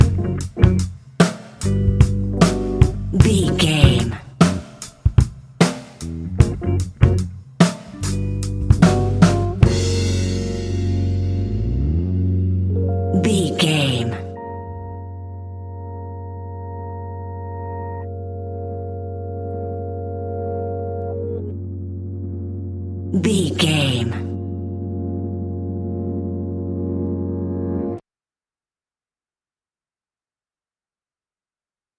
Epic / Action
Fast paced
In-crescendo
Uplifting
Ionian/Major
F♯
hip hop